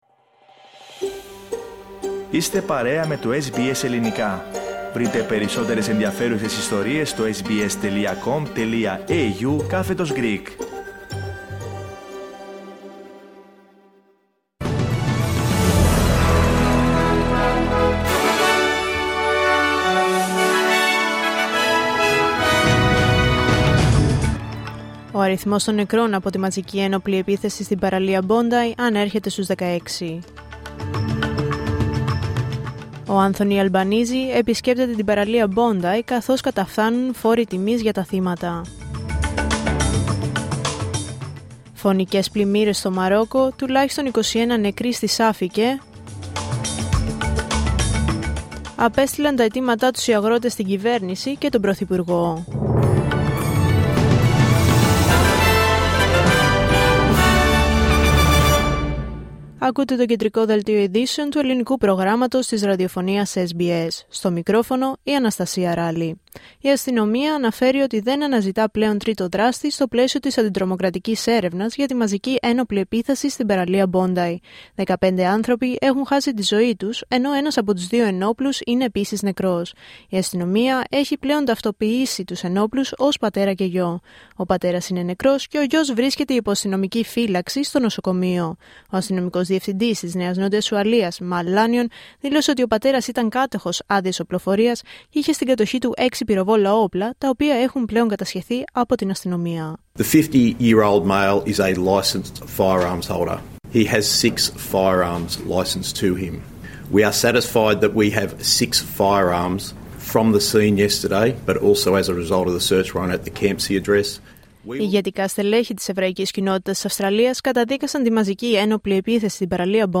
Δελτίο Ειδήσεων Δευτέρα 15 Δεκεμβρίου 2025